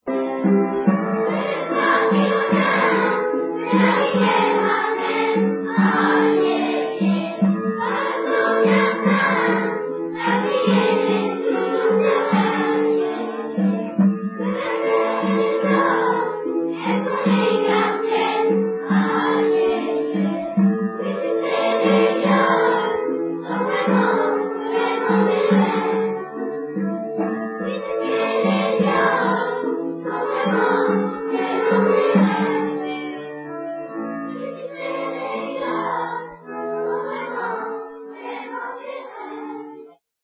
300 élèves de Dunkerque
Vu le nombre des enfants, le résultat n'a pas la qualité d'un véritable travail de chorale, mais chacun essaie d'y mettre de la bonne volonté et des notes plutôt justes !
à la salle de la Concorde de Petite-Synthe (près de la mairie)
Le jour du concert